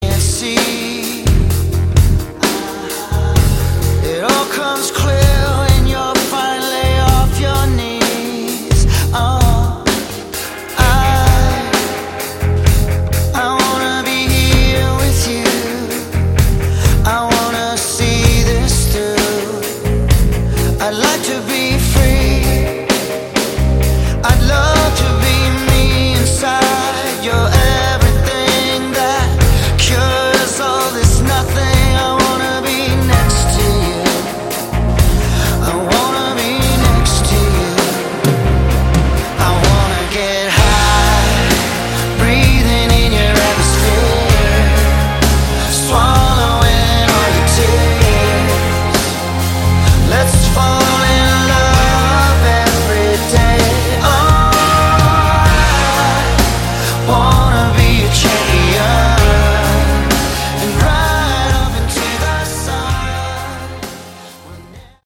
Category: Melodic Rock
Far too mellow.